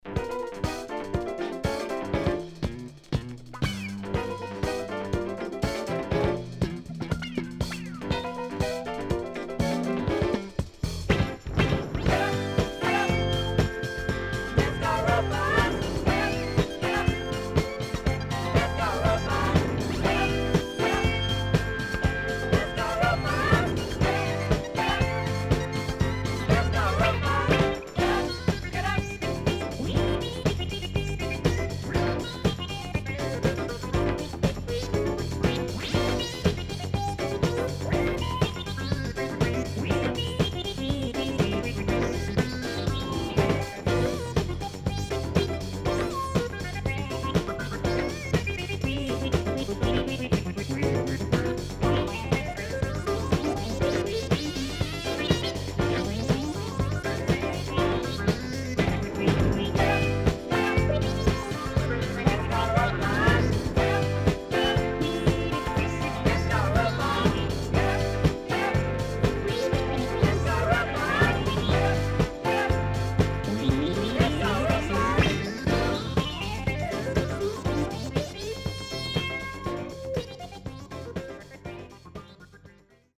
77年Funky Disco Classic.40825